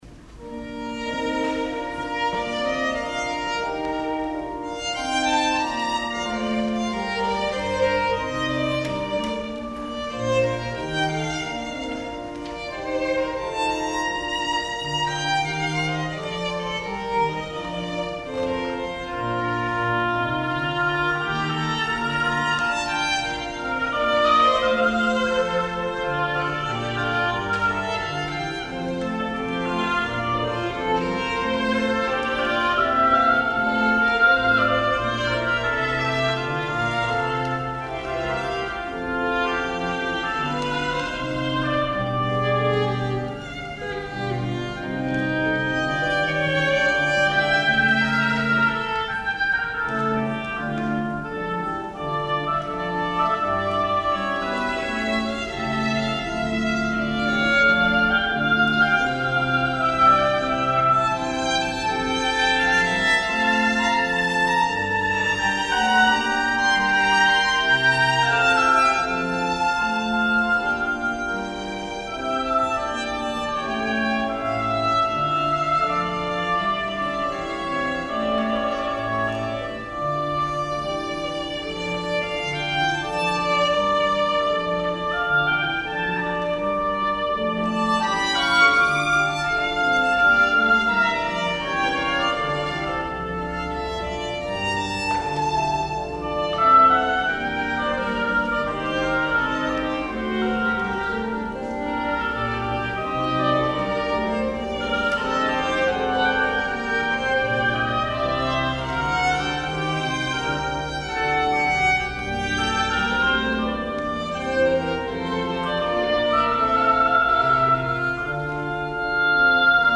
violin
oboe